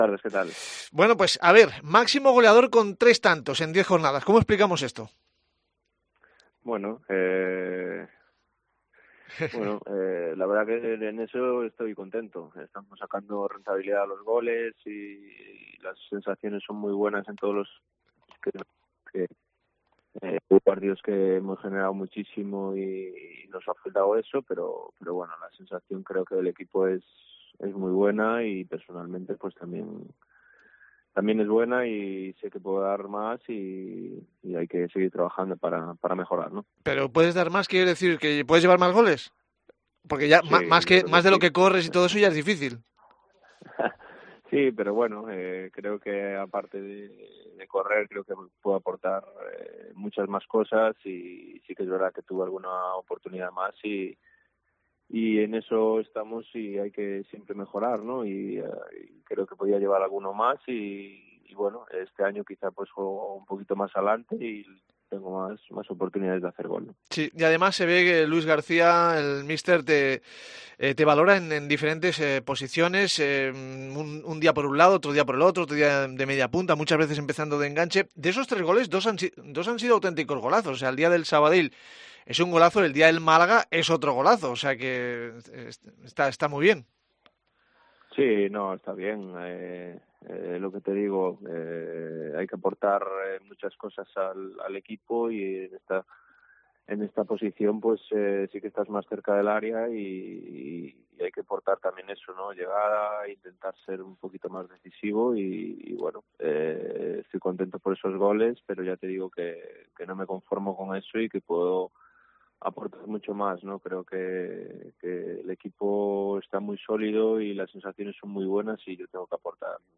Entrevista a Dani Rodríguez